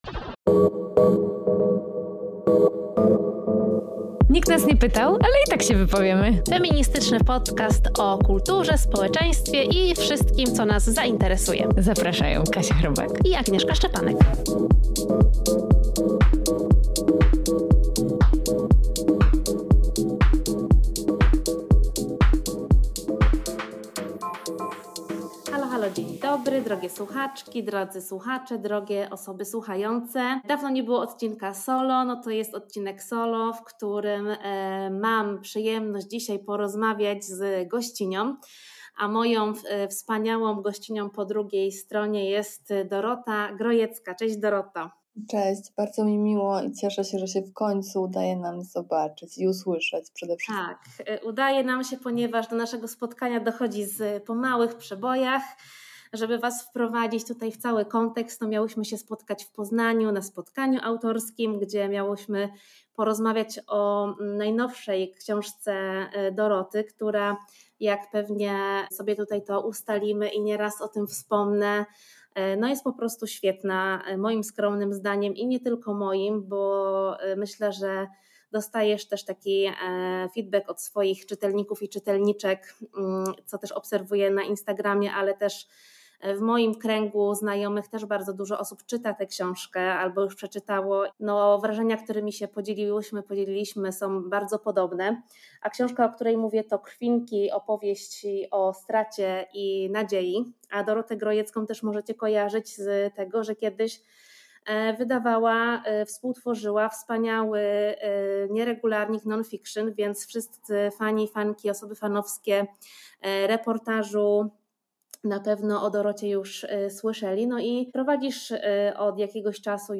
… continue reading 154 حلقات # Społeczeństwo # Estrada Poznańska # Kobiety # Kultura # Feminizm # Women # Girlpower # Książki # Rozmowy # Sztuka